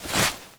melee_holster_temp2.wav